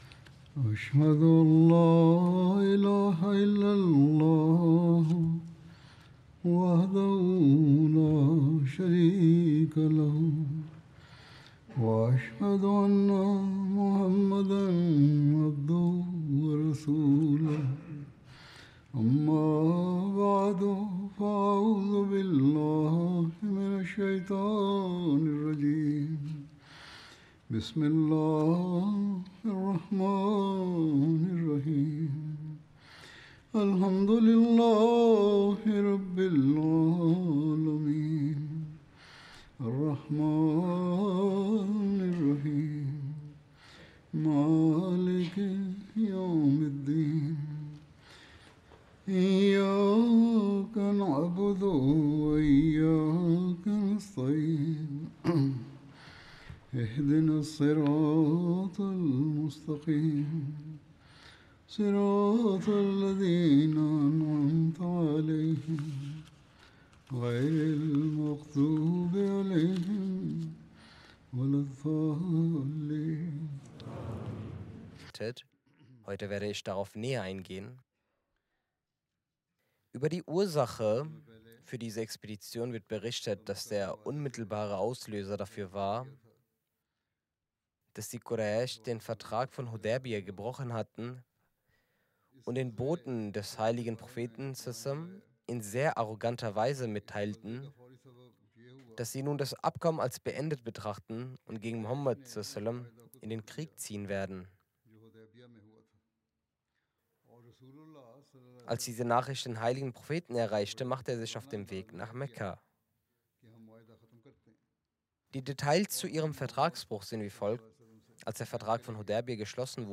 German Translation of Friday Sermon delivered by Khalifatul Masih